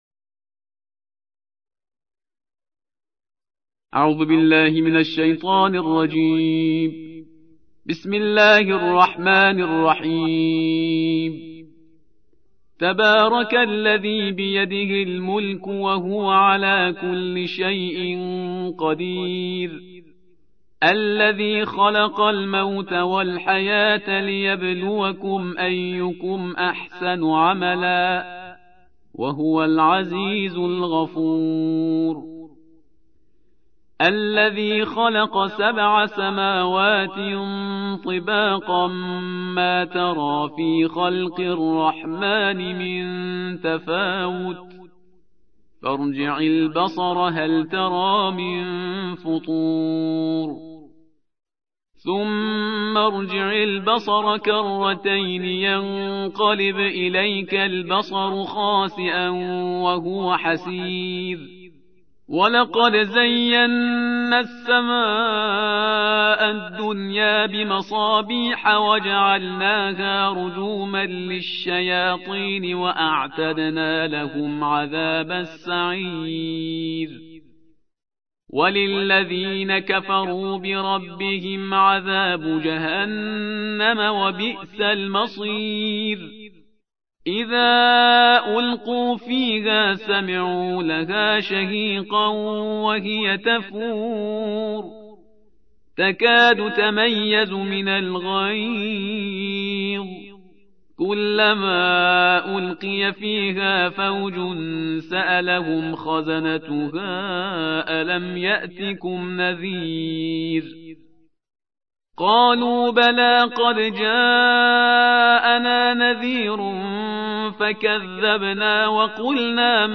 تحميل : الجزء التاسع والعشرون / القارئ شهريار برهيزكار / القرآن الكريم / موقع يا حسين